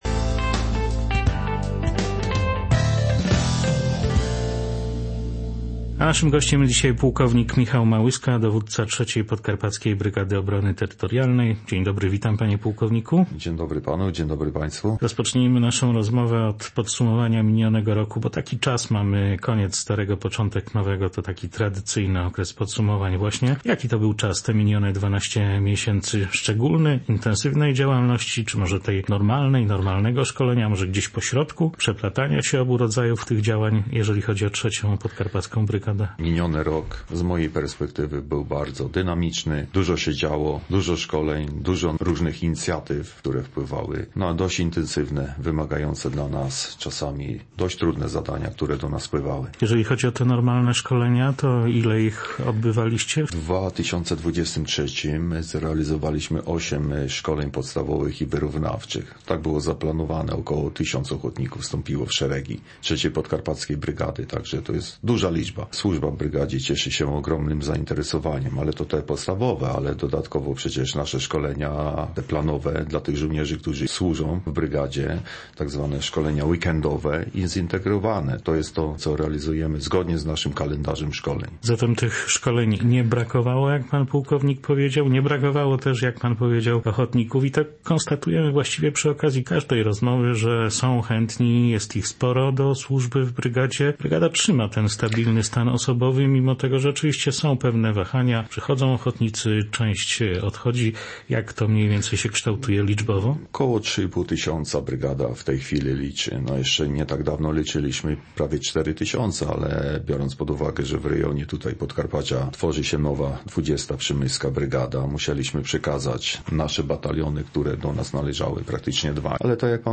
gościem porannego Kalejdoskopu